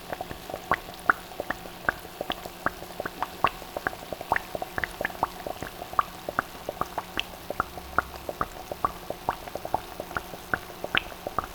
boiling_bubbling_water_gurgle_02.WAV